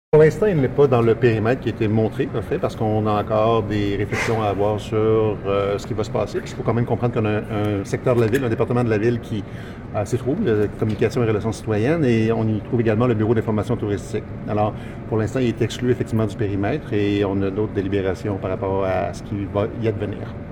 Interrogé à savoir si cet immeuble faisait partie du projet de réaménagement de la Place des anciens combattants, le maire Guy Caron nous a répondu ce qui suit: